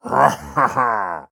Minecraft Version Minecraft Version snapshot Latest Release | Latest Snapshot snapshot / assets / minecraft / sounds / mob / pillager / celebrate1.ogg Compare With Compare With Latest Release | Latest Snapshot
celebrate1.ogg